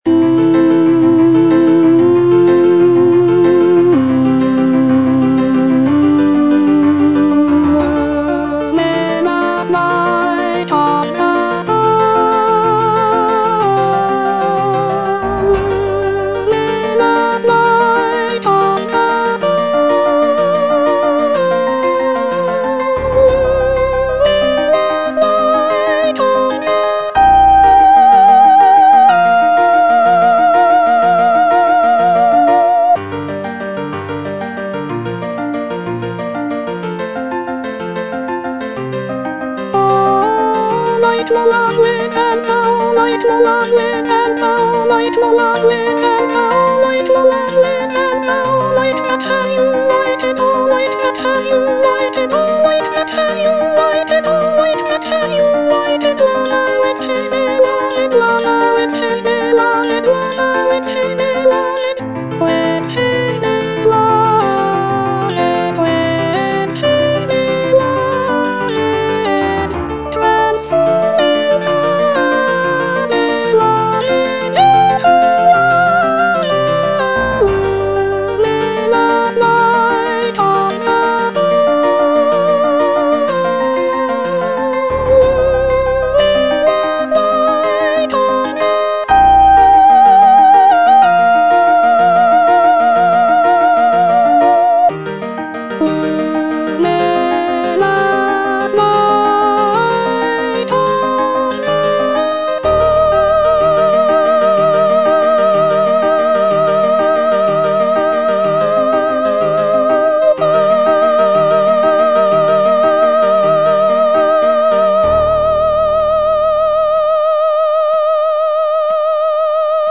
soprano I) (Voice with metronome